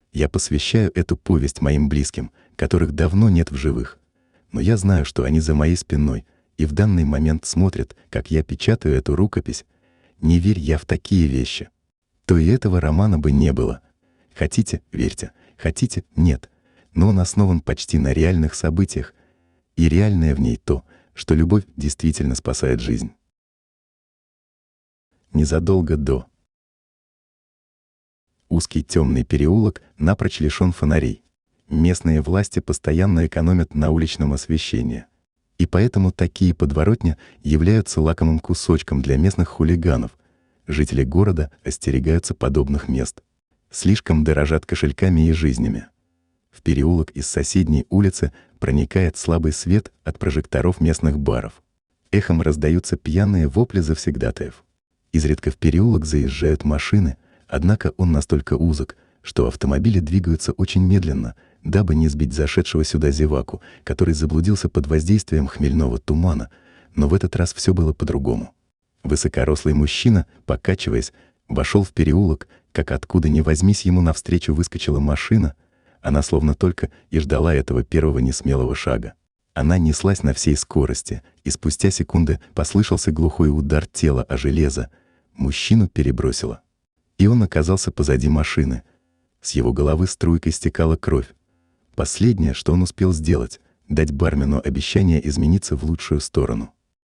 Аудиокнига Роксана делает выбор. На что способны любовь и прощение?